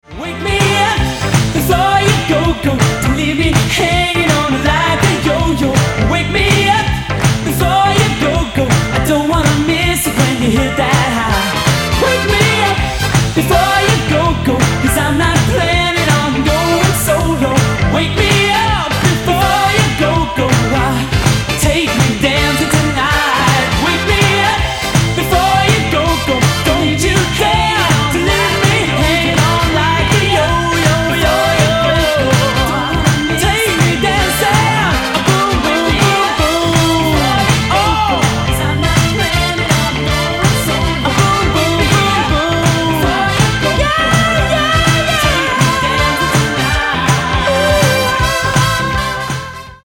• Качество: 320, Stereo
красивый мужской голос
веселые
80-е